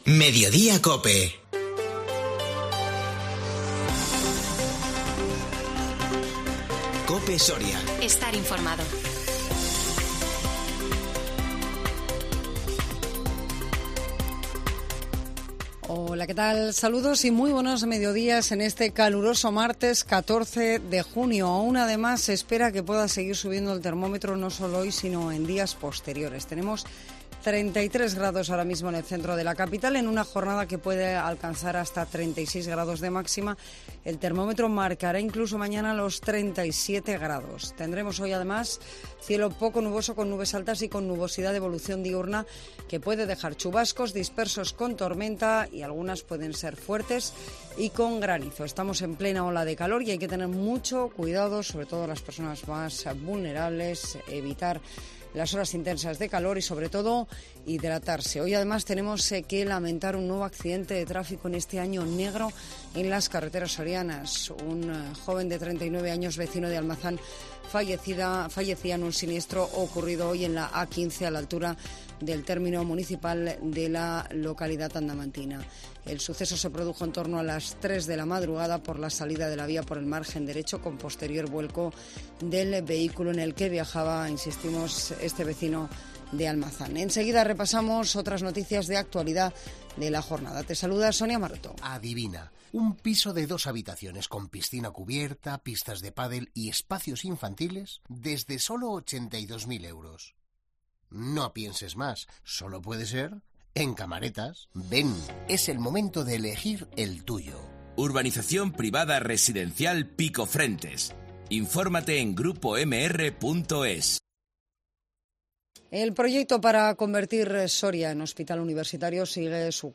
INFORMATIVO MEDIODÍA COPE SORIA 14 JUNIO 2022